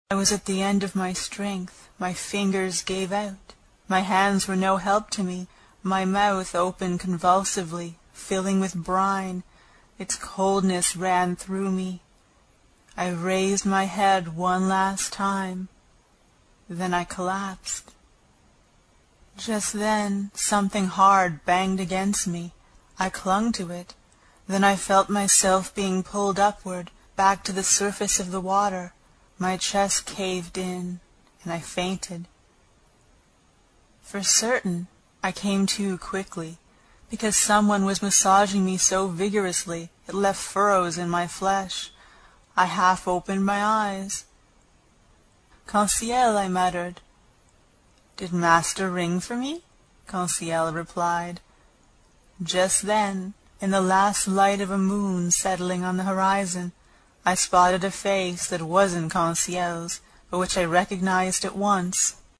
英语听书《海底两万里》第87期 第7章 一种从未见过的鱼(10) 听力文件下载—在线英语听力室
在线英语听力室英语听书《海底两万里》第87期 第7章 一种从未见过的鱼(10)的听力文件下载,《海底两万里》中英双语有声读物附MP3下载